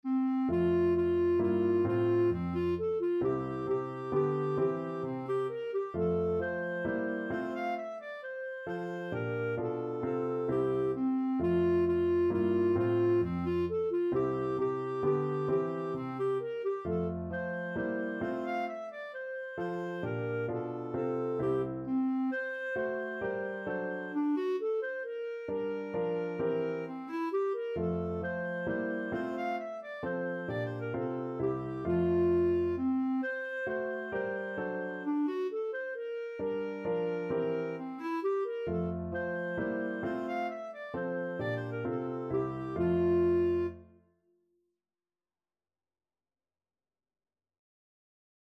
= 132 Allegro (View more music marked Allegro)
3/4 (View more 3/4 Music)
C5-F6
Classical (View more Classical Clarinet Music)